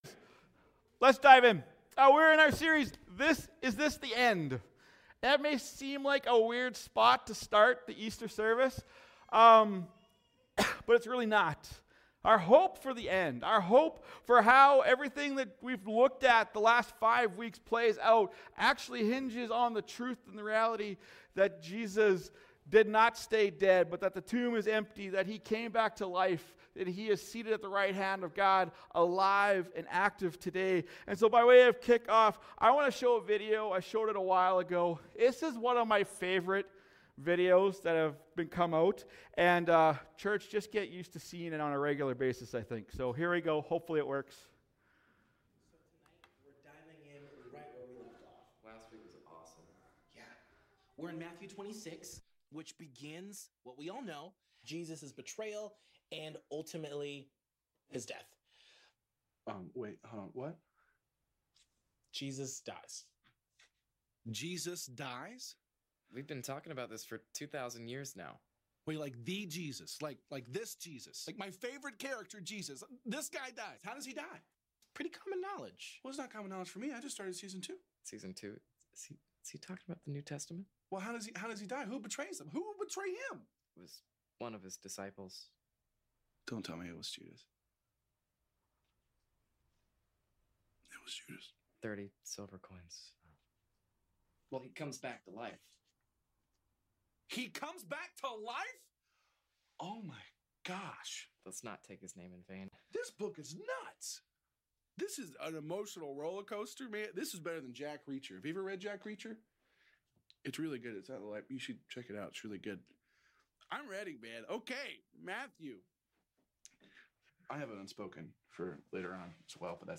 Sermons | OneChurch
Easter Sunday 2026